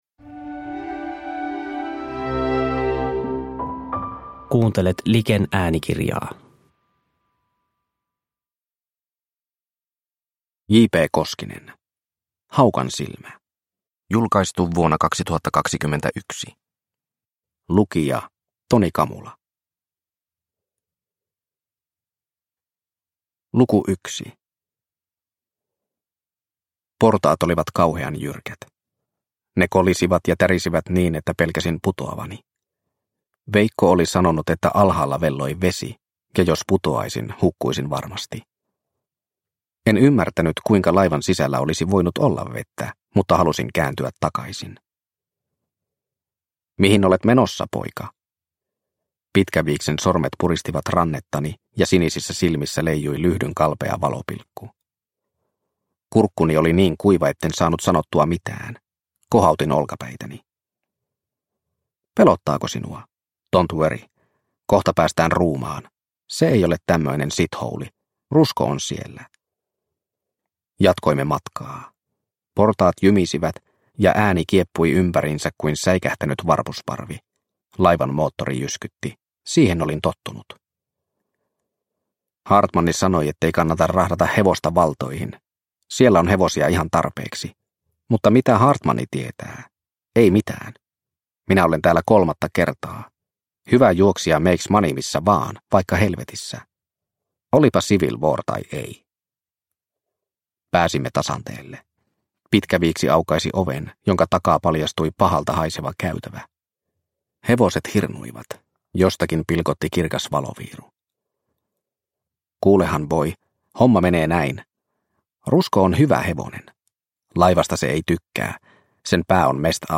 Haukansilmä – Ljudbok – Laddas ner